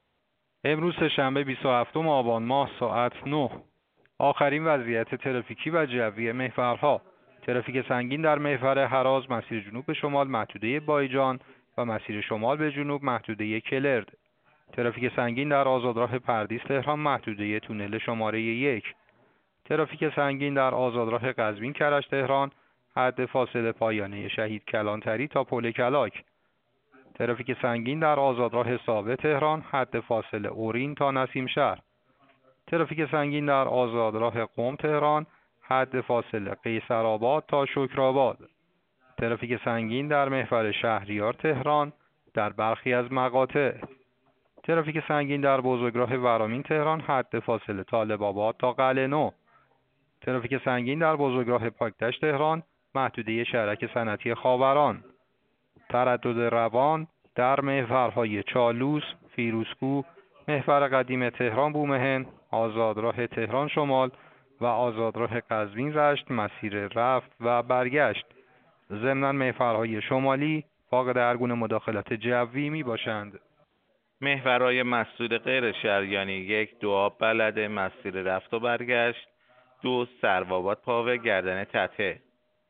گزارش رادیو اینترنتی از آخرین وضعیت ترافیکی جاده‌ها ساعت ۱۳ بیست و ششم آبان؛